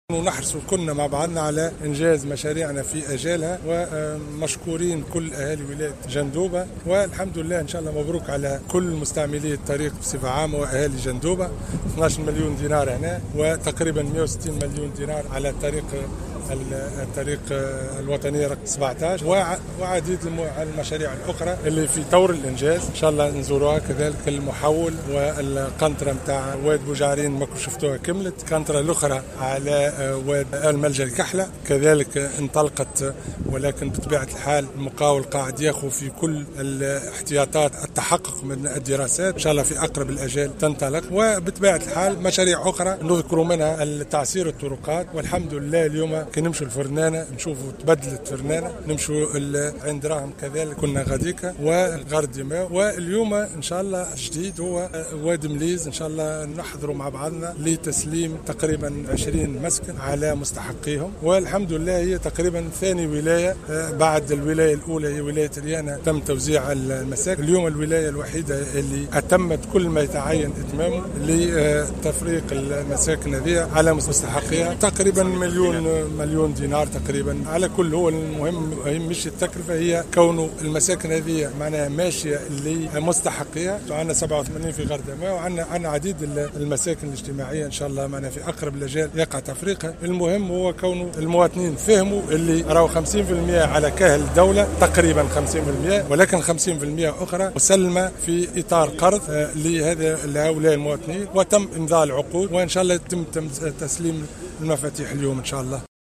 وقال العرفاوي في نقطة إعلامية، أنّ وزارة التجهيز رصدت منذ 2012، نحو 160 مليون دينار لمعالجة الانزلاقات الأرضية التي جدّت بالطريق الوطنية رقم 17.